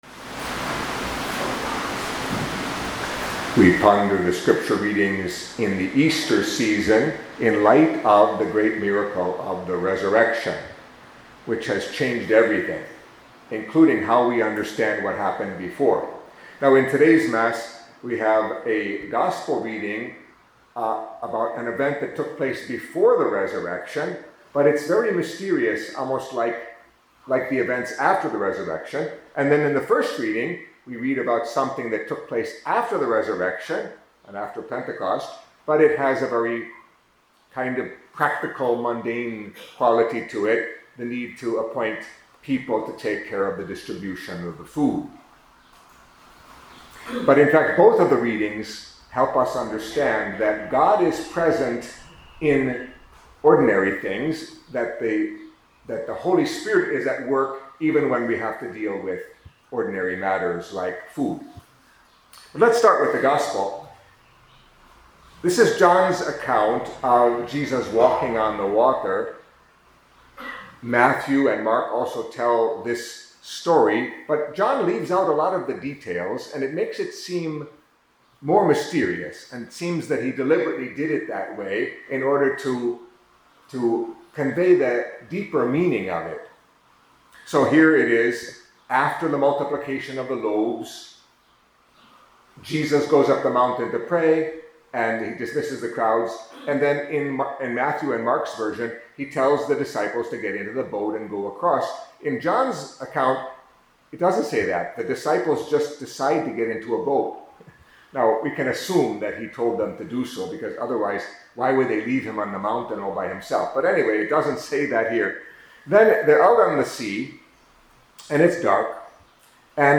Catholic Mass homily for Saturday in the Second Week of Easter